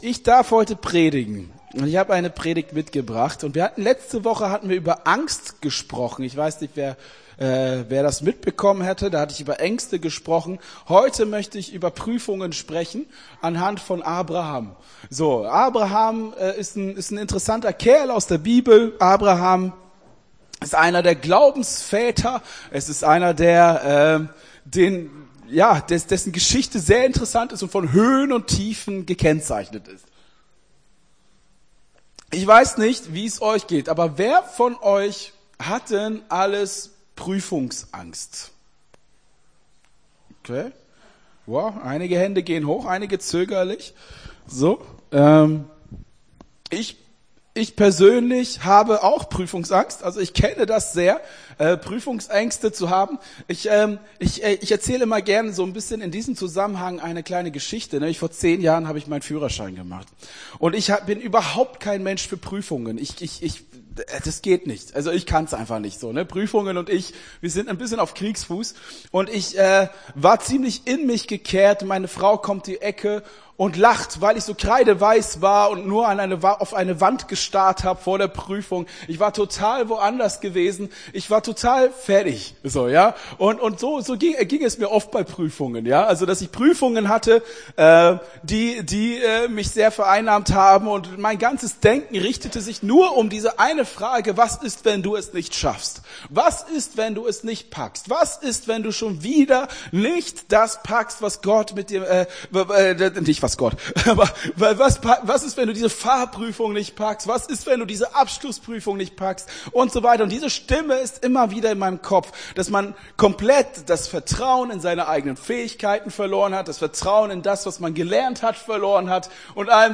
Gottesdienst 12.02.23 - FCG Hagen